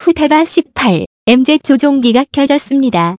그래서 전문 성우의 목소리로 문장을 읽어주는 TTS 소프트웨어를 찾아봤는데, 마침 250자까지는 공짜(?)로 되는 착한 곳이 있더군요~
따라서 위의 TTS 프로그램으로 소리를 내면서 동시에 GoldWave로 녹음을 하면 되는 것입니다.